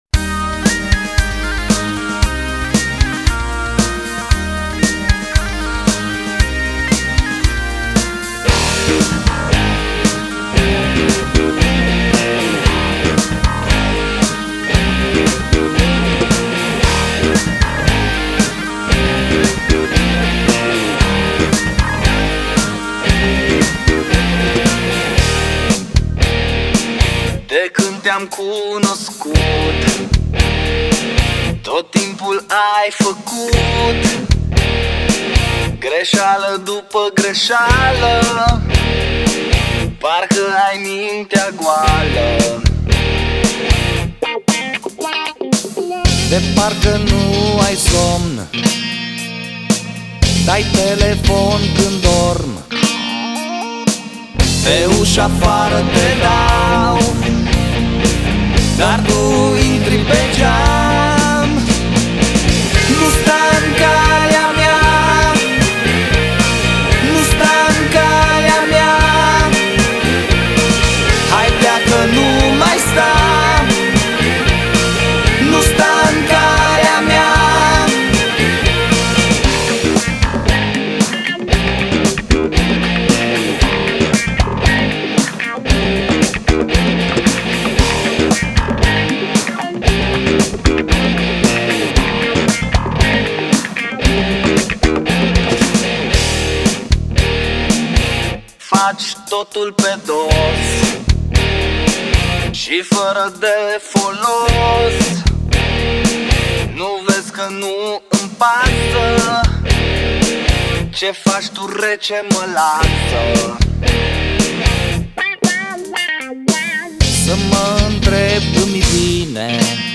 flaut, voce